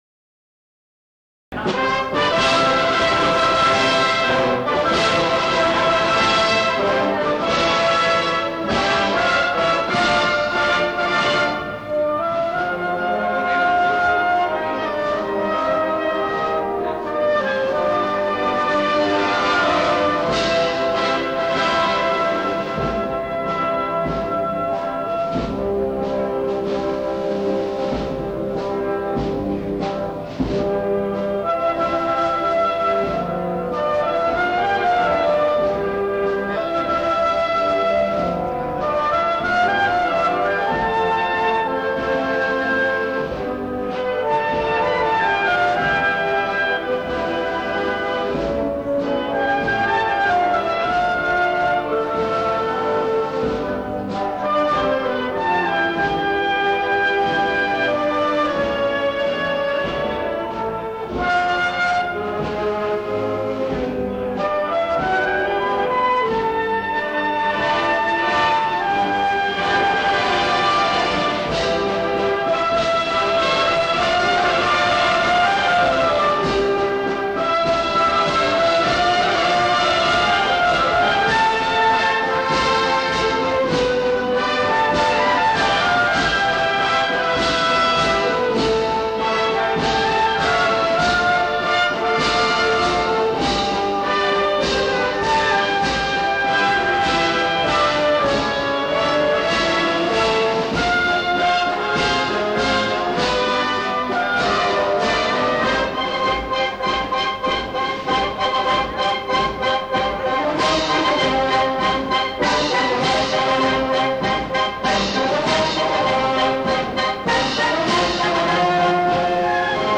Marchas procesionales
Grabación en directo realizada en la procesión de la Santísima Virgen, por la Banda Municipal de la Puebla del río.